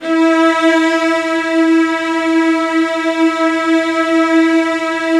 CELLOS F#4-R.wav